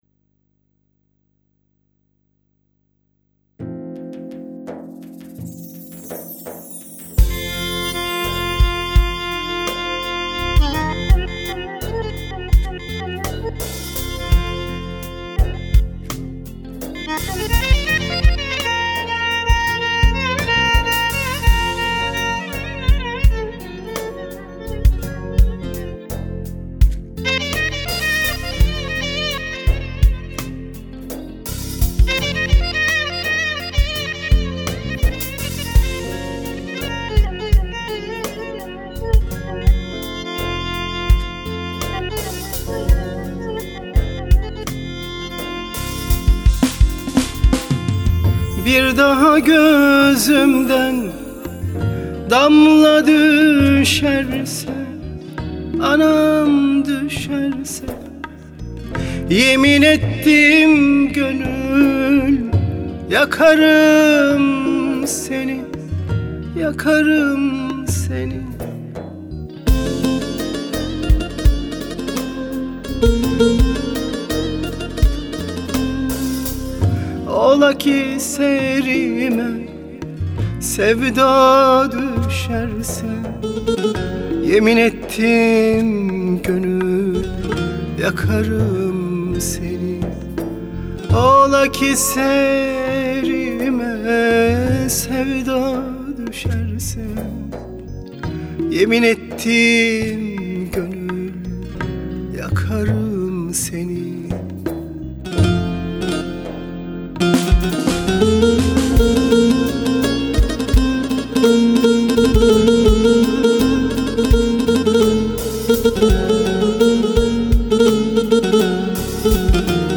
Halk Müziği